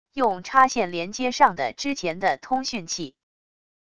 用插线连接上的之前的通讯器wav音频